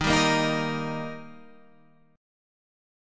D# Chord
Listen to D# strummed